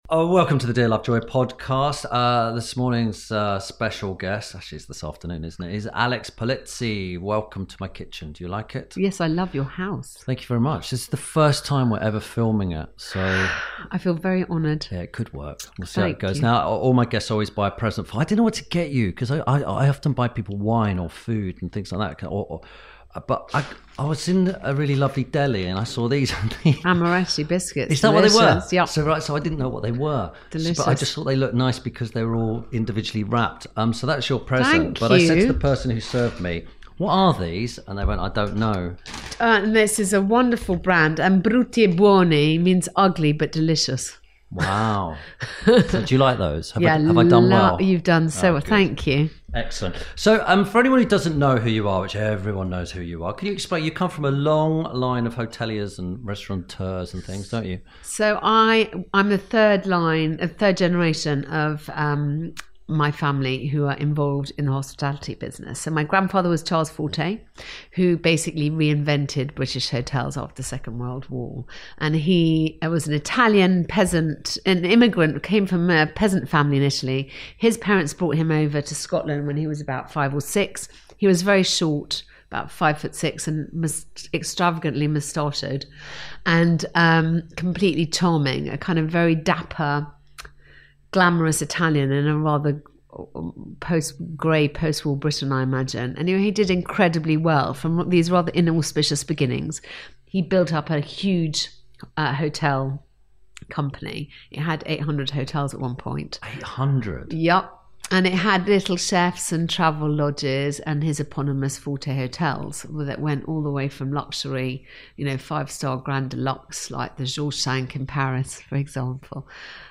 Ep. 63 – ALEX POLIZZI – Checking The Mattress Covers And Other Hotel Tips – INTERVIEW SPECIAL
This week Tim Lovejoy talks to hotelier and TV presenter Alex Polizzi.